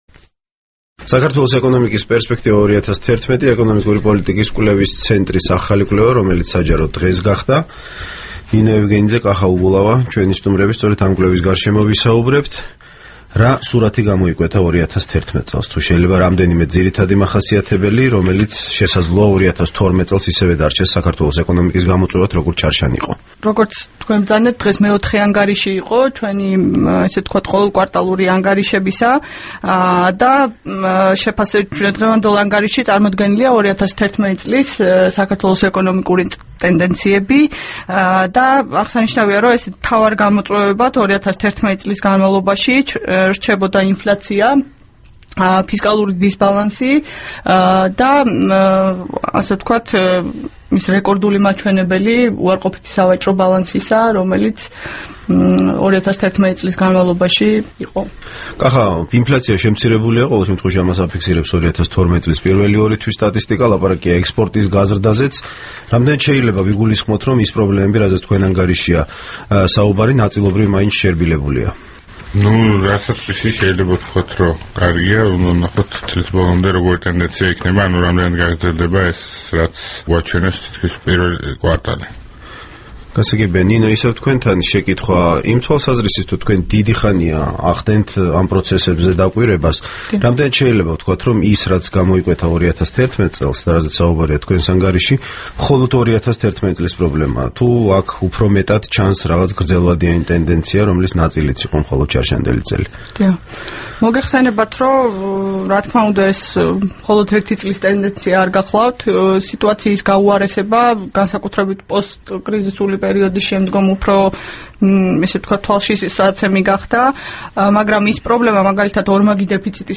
ყოველკვირეული დისკუსია